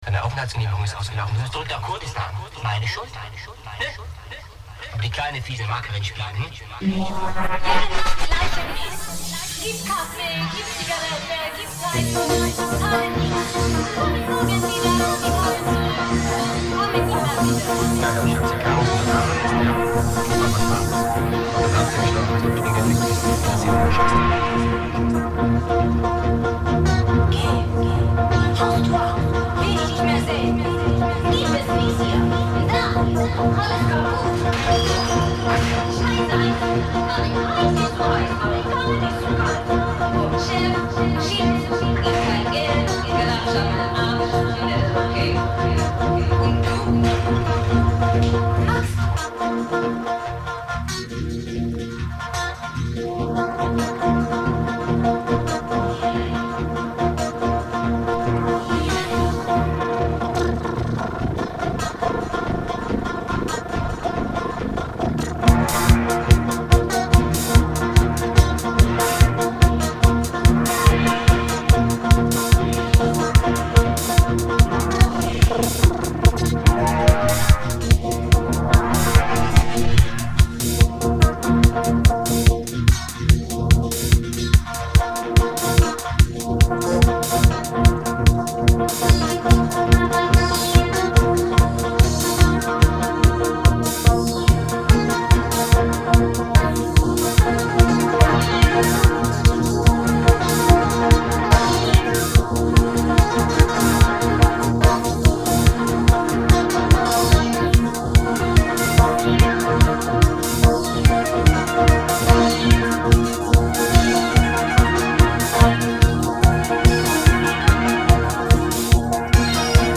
voix & guitares